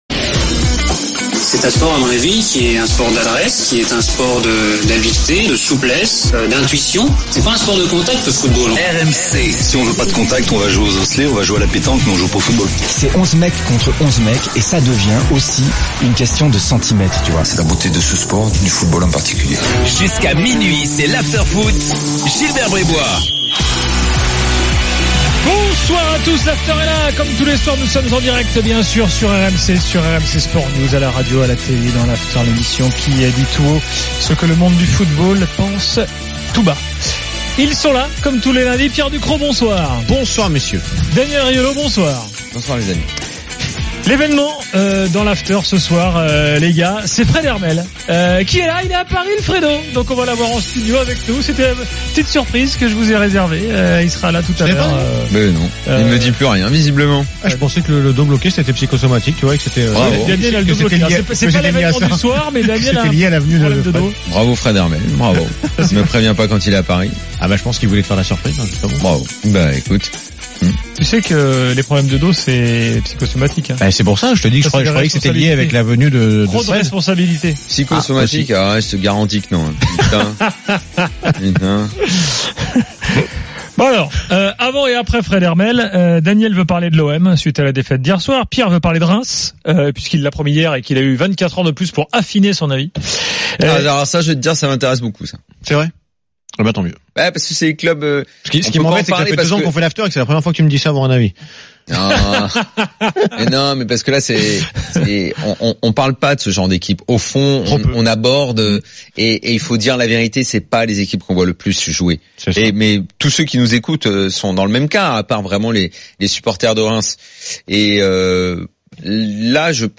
le micro de RMC est à vous !